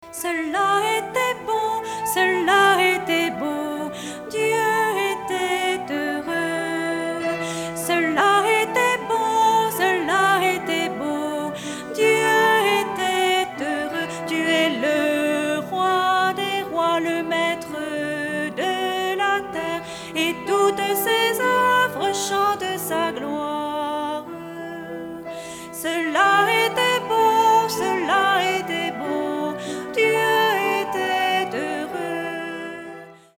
Des chants pour enfants, pleins de joie et de fraîcheur.
Chantés par des enfants